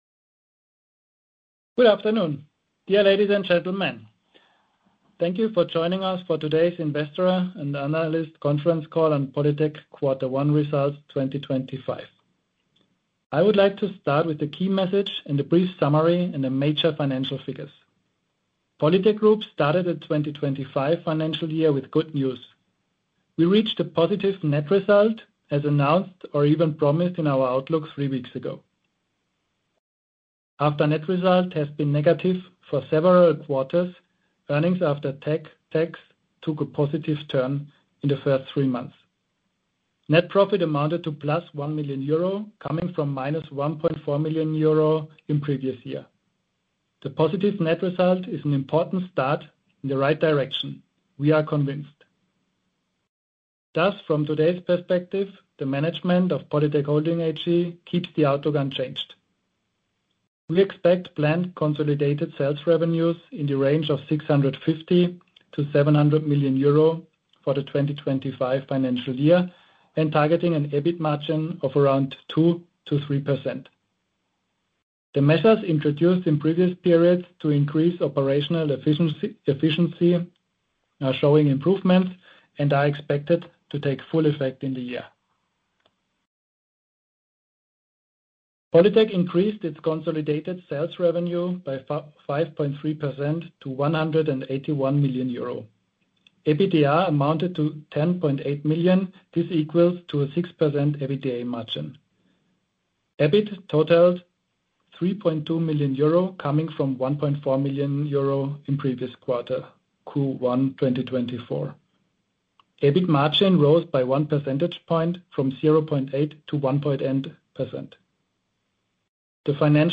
TELEFONKONFERENZ